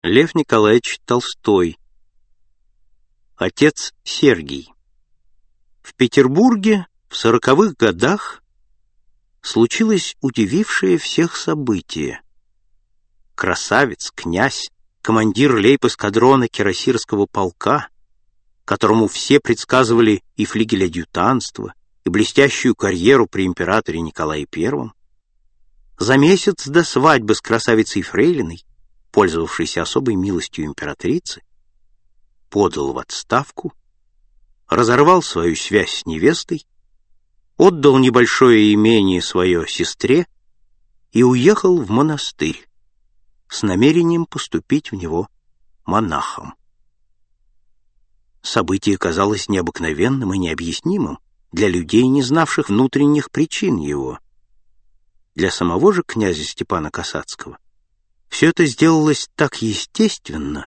Аудиокнига Отец Сергий | Библиотека аудиокниг